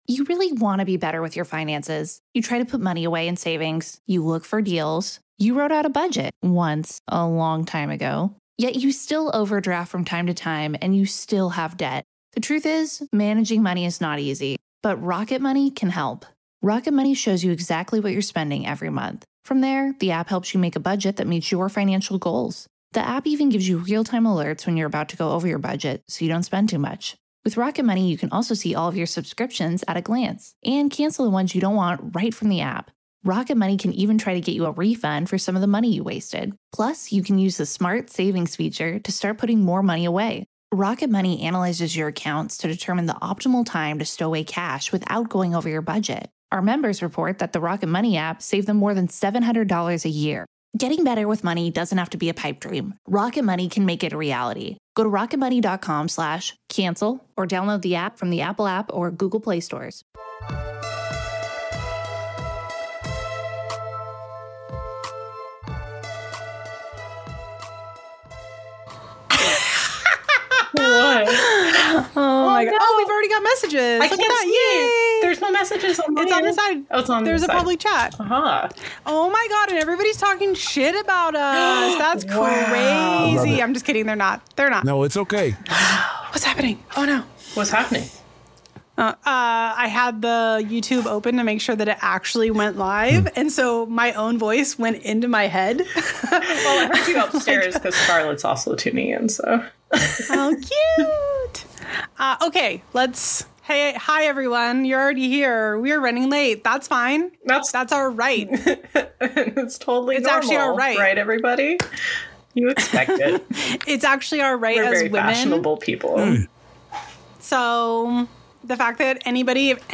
Coffee and Cauldrons / Live Episode